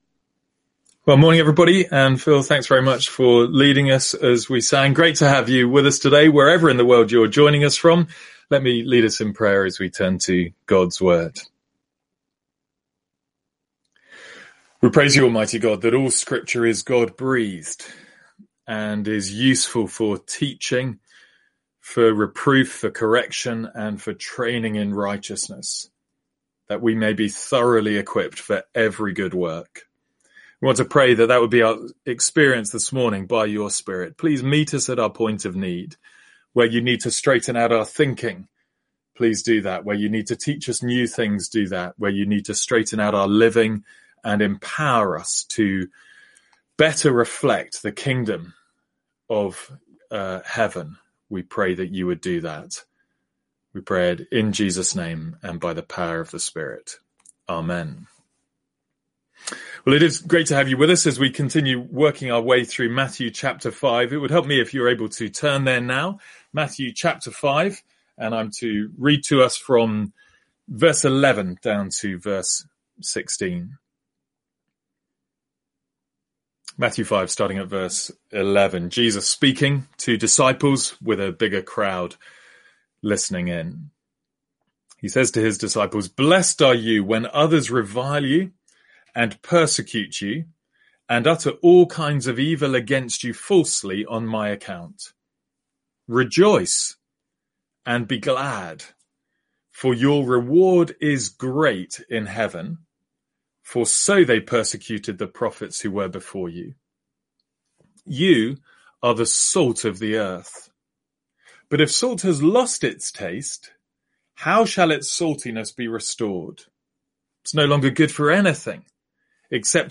Sermons | St Andrews Free Church
From our morning service in The Sermon on the Mount.